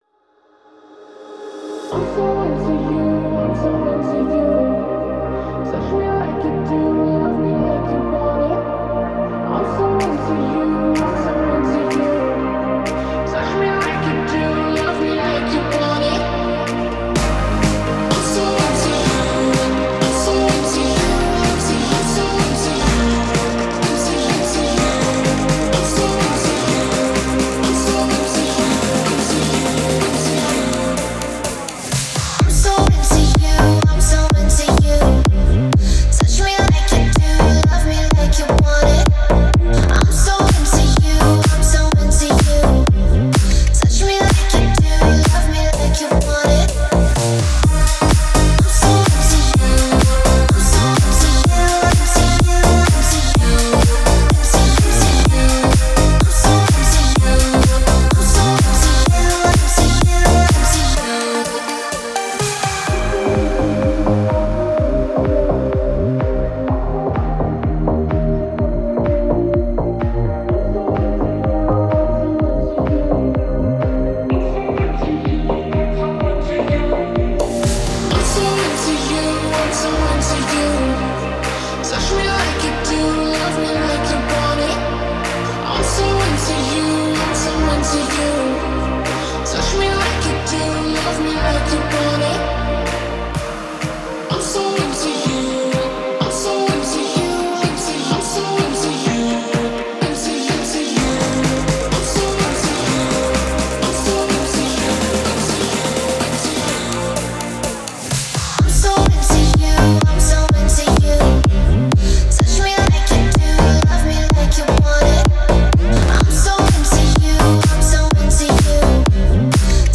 это зажигательная поп-песня